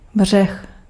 Ääntäminen
Tuntematon aksentti: IPA: /bɔʁ/